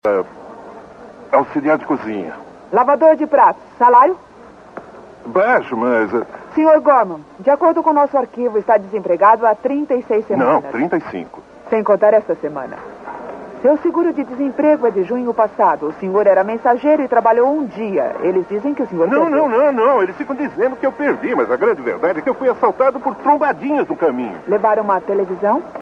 How to improve muffled audio - VideoHelp Forum
I got this kinda old audio track recorded from TV and it sounds muffled, as if it was coming from inside a glass bubble.
I did this in Audacity using the Classic EQ and Amplify effect, I boosted everything about 6dB: